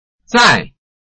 臺灣客語拼音學習網-客語聽讀拼-饒平腔-開尾韻
拼音查詢：【饒平腔】zai ~請點選不同聲調拼音聽聽看!(例字漢字部分屬參考性質)